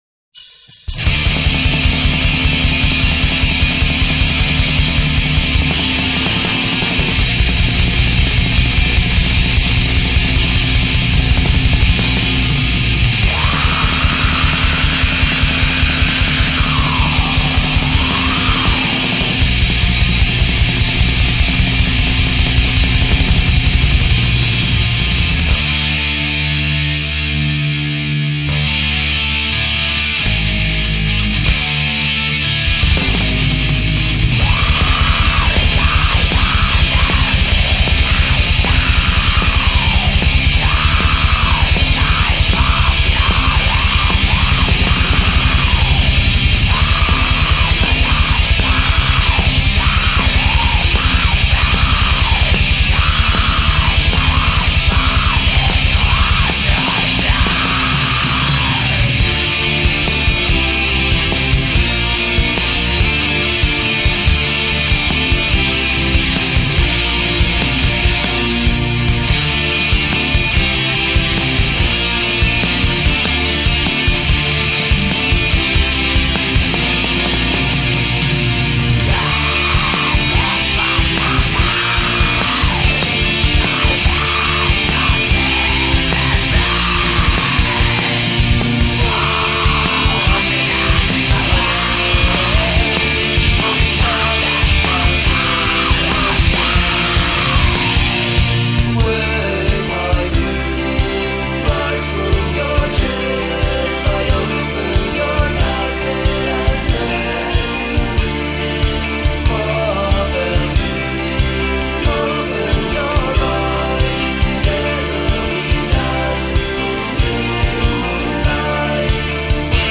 Black Metal